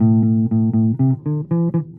贝斯吉他 " Basse 13
当然不是最好的样本，但对于培训来说，它是安静的好。
标签： 低音 电动 样品
声道立体声